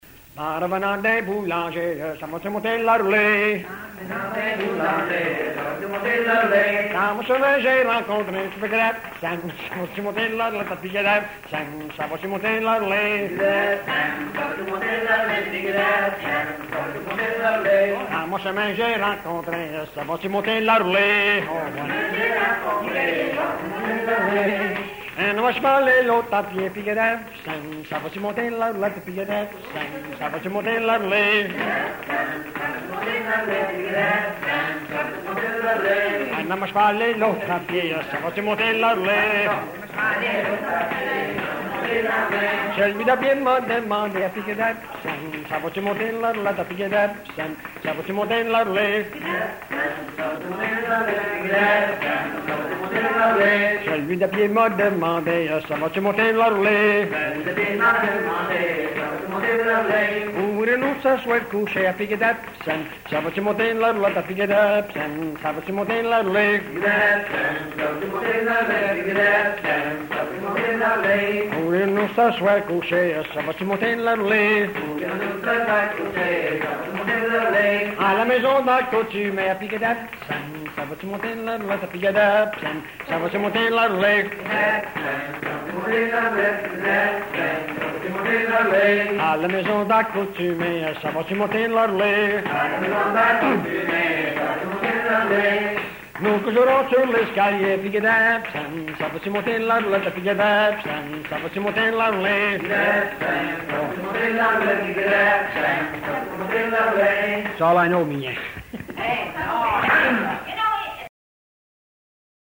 Folk Songs, French--New England